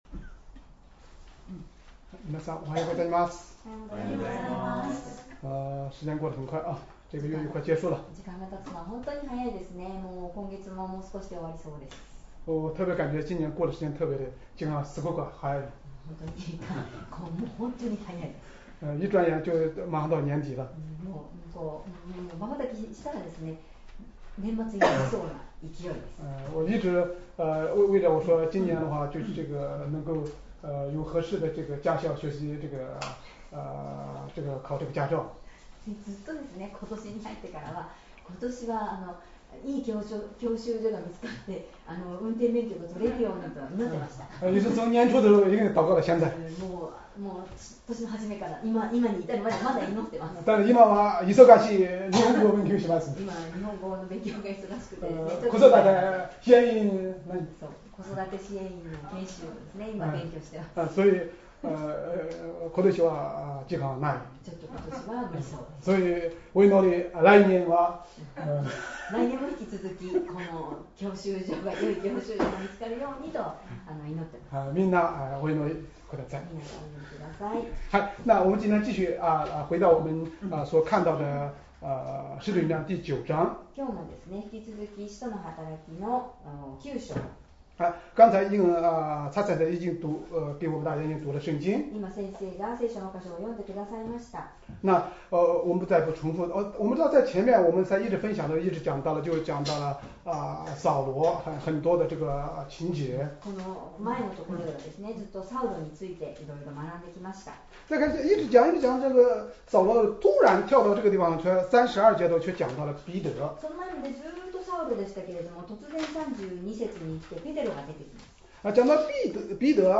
Sermon
2025年10月19日 主日礼拝 説教 「ペテロ、アイネアを癒やす」 聖書 使徒の働き9章 32－35節 9:32 さて、ペテロがあらゆるところを巡回していたときのことであった。